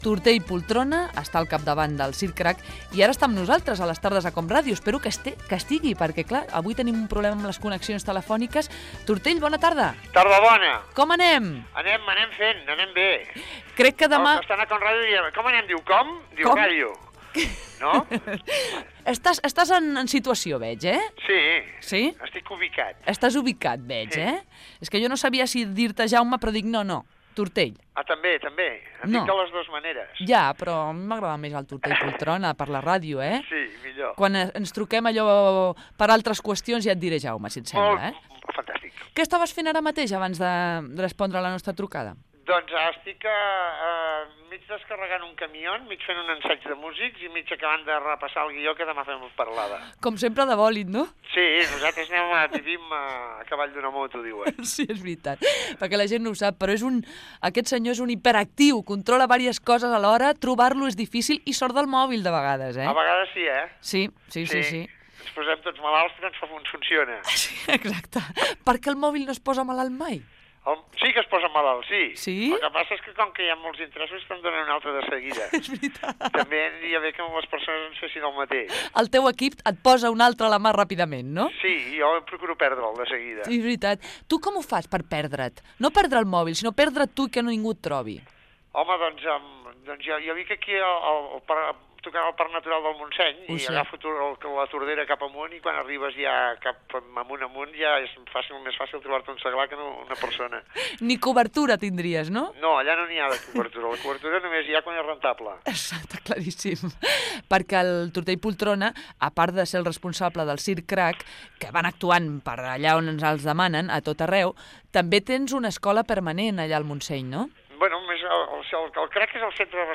Fragment d'una entrevista al pallasso Tortell Poltrona (Jaume Mateu) del Circ Cric.
Entreteniment